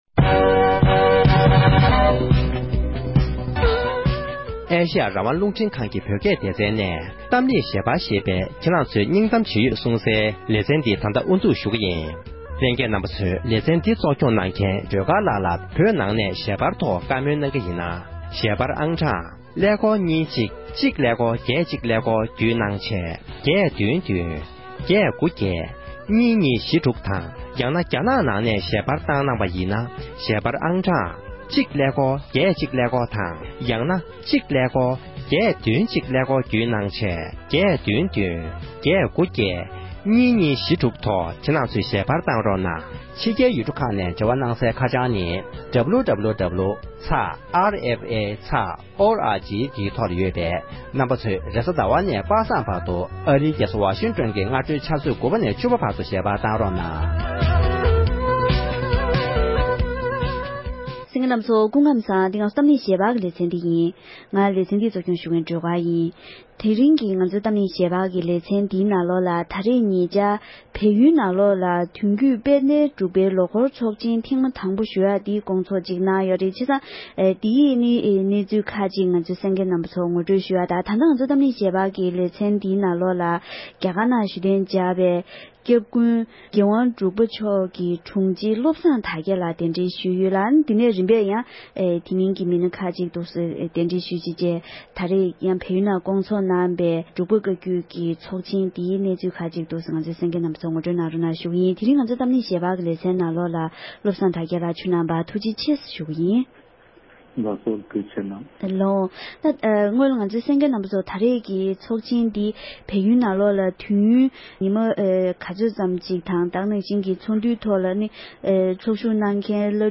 འབྲེལ་ཡོད་མི་སྣའི་ལྷན་བཀའ་མོལ་ཞུས་པར་གསན་རོགས༎